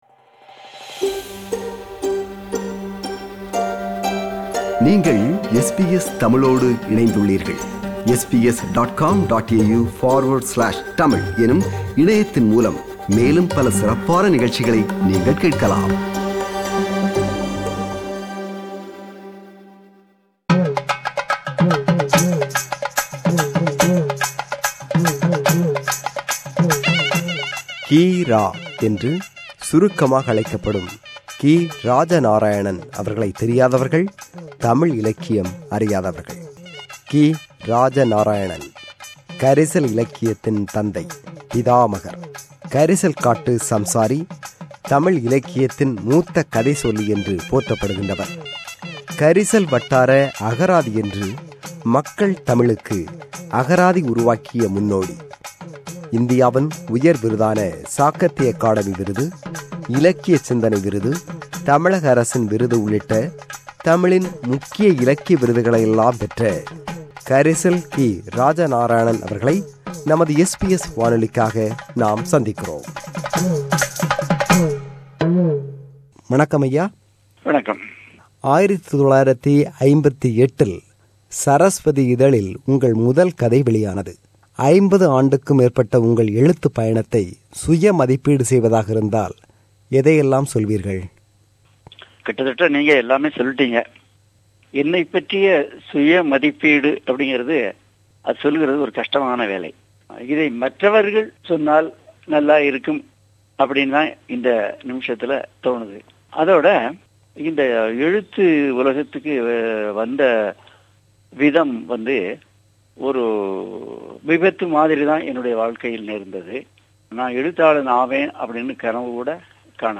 தமிழ் இலக்கிய உலகின் ‘முன்னத்தி ஏர்’, ‘தமிழ் எழுத்துலகின் பீஷ்மர்’, ‘தலைசிறந்த கதைசொல்லி’, ‘கரிசல் இலக்கியத்தின் பிதாமகர்’ என்றெல்லாம் போற்றப்படும் கி.ரா. என்கிற கி.ராஜநாராயணன் அவர்கள் (வயது 98) கடந்தவாரம் புதுச்சேரியில் காலமானார். கி.ரா அவர்கள் 10 ஆண்டுகளுக்கு முன்பு (2011 ஆம் ஆண்டு) SBS - தமிழ் ஒலிபரப்புக்கு வழங்கிய நேர்முகத்தின் முதற்பாகம்.